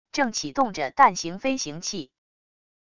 正启动着蛋型飞行器wav音频